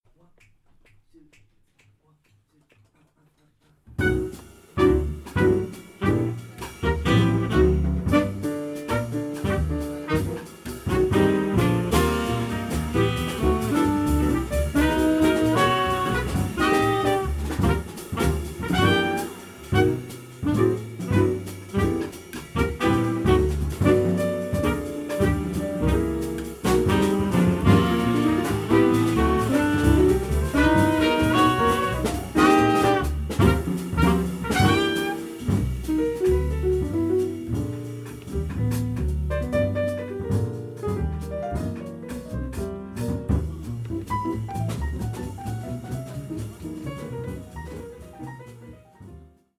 trumpet
sax
piano
bass
drums